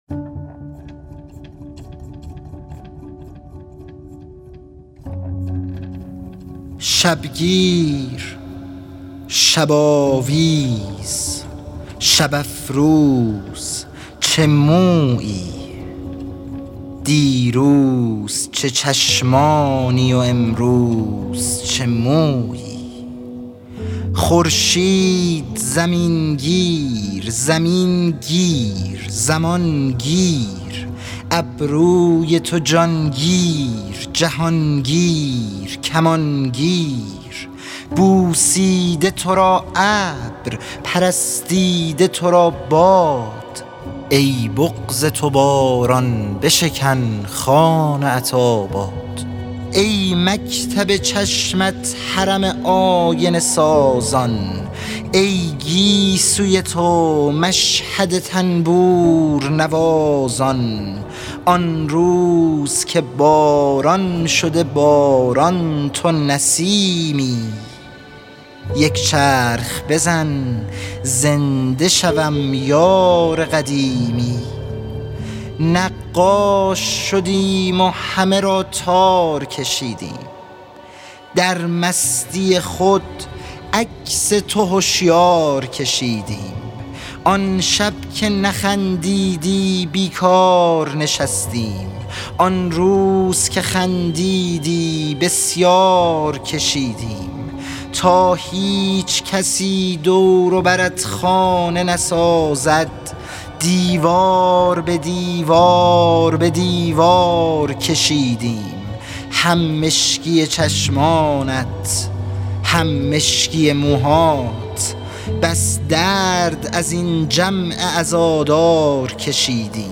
اطلاعات دکلمه